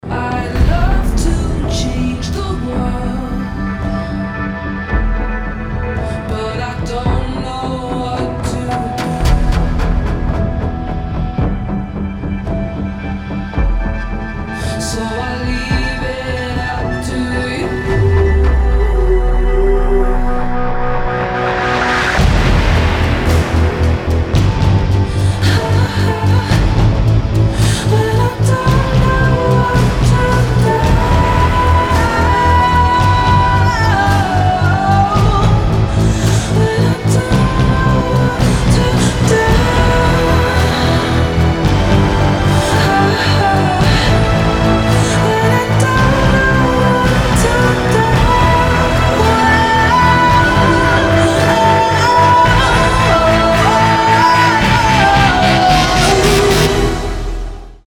• Качество: 320, Stereo
спокойные
OST